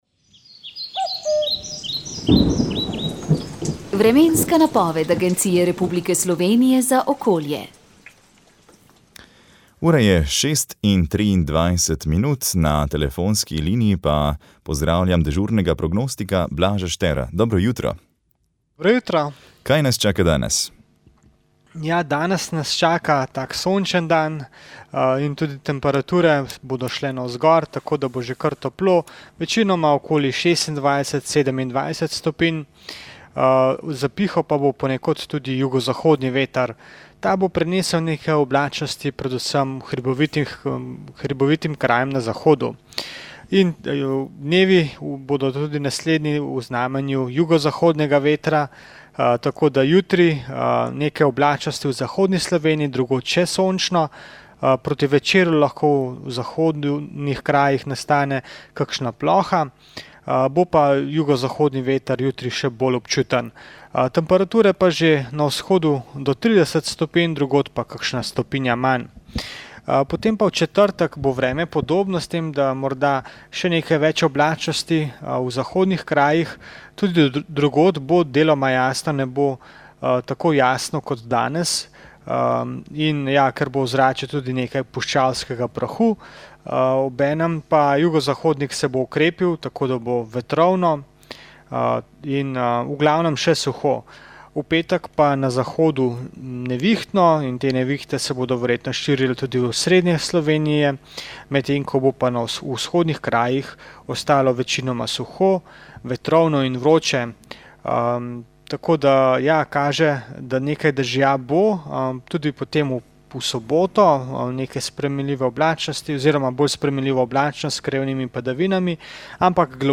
Vremenska napoved 24. avgust 2025